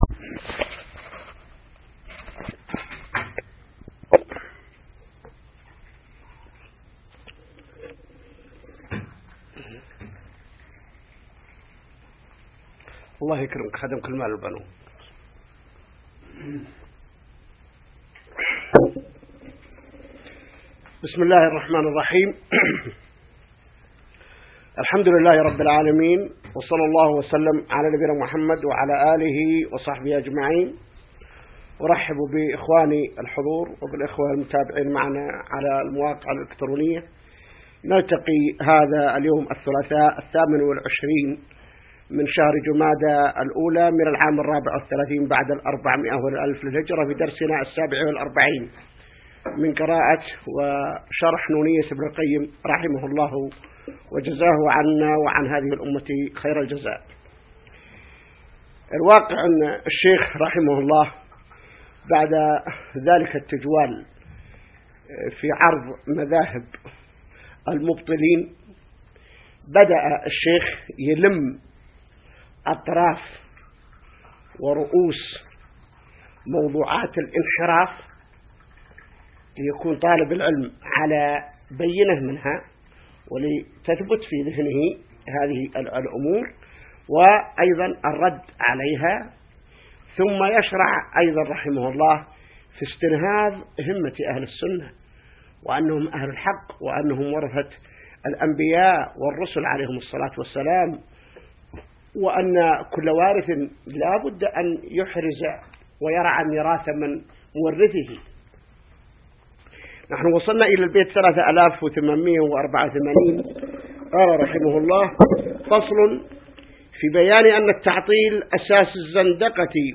الدرس 47 من شرح نونية ابن القيم | موقع المسلم